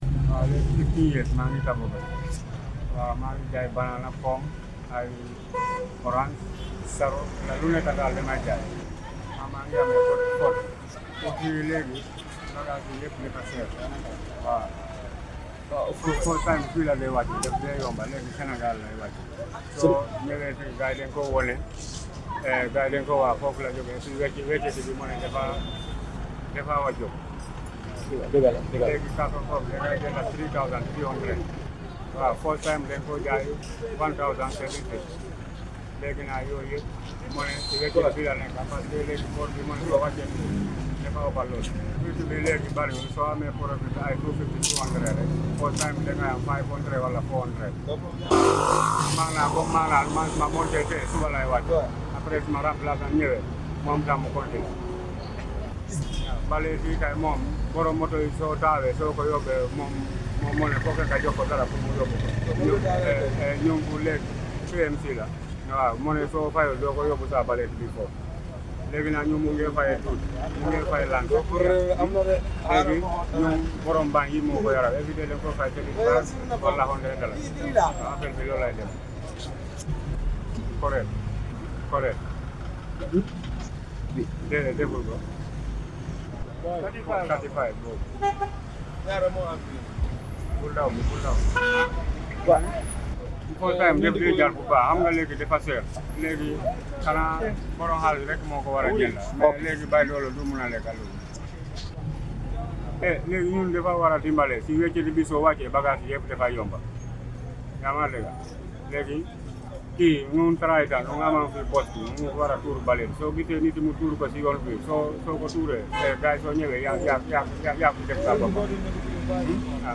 Hit the Play button below and listen (in Wollof) to a fruit seller talk about his stall and challenges he faces as a roadside vendor.
Fruit-sellers-voice.mp3